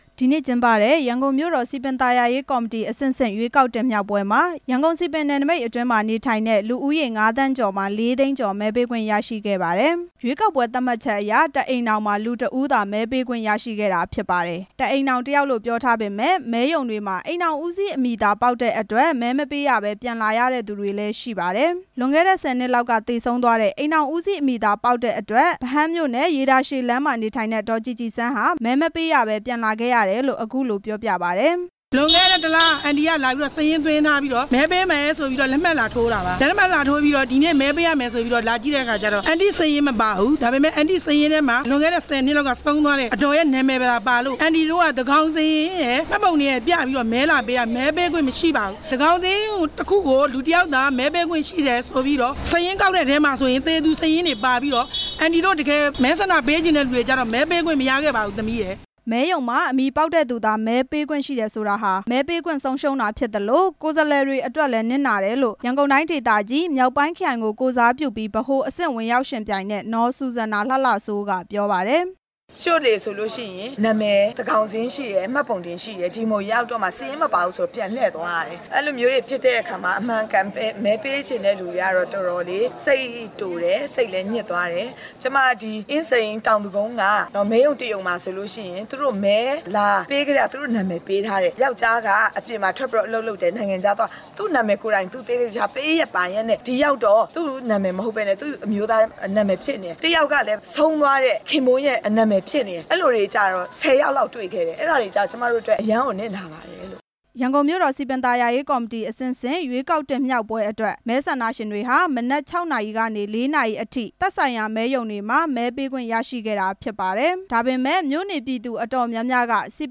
ရန်ကုန်မြို့တော်စည်ပင် ရွေးကောက်ပွဲအကြောင်း တင်ပြချက်